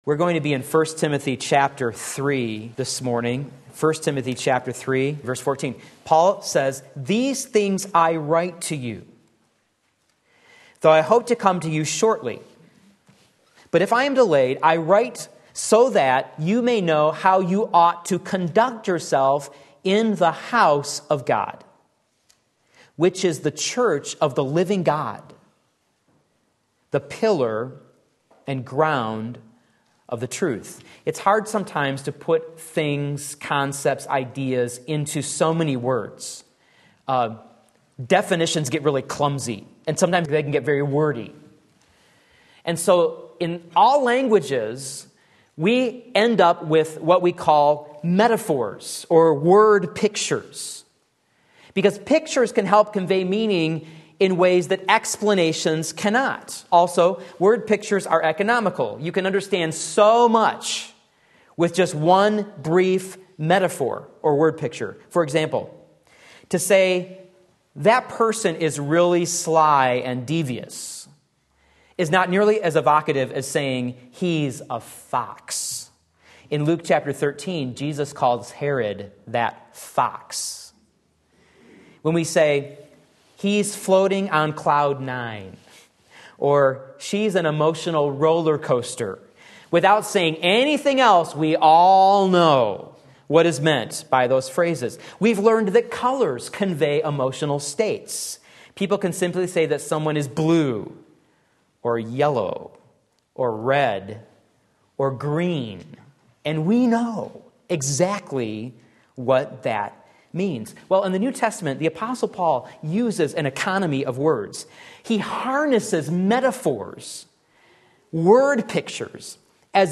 Sermon Link
Pictures of a New Testament Church 1 Timothy 3:15 Sunday Morning Service